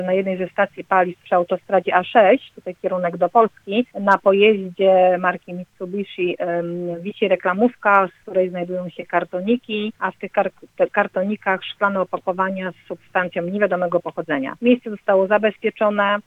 mówi rzecznik